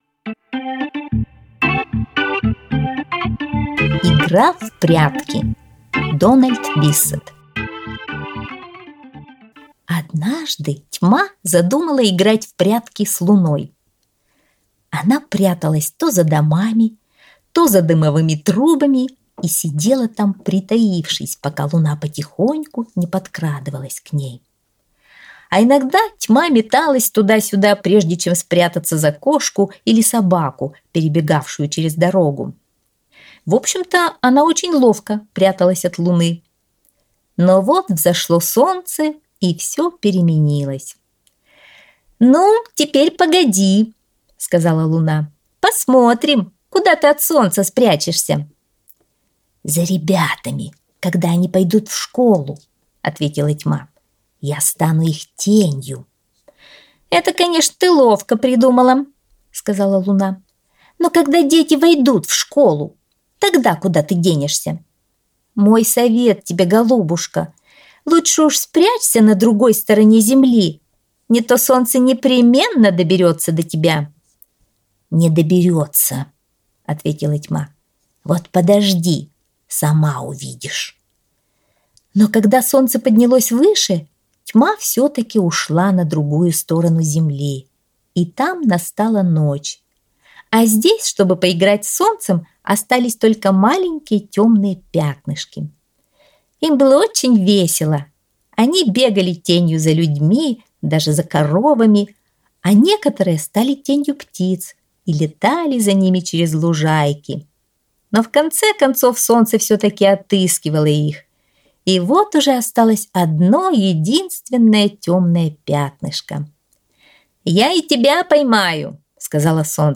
Аудиосказка «Игра в прятки — Биссет Дональд»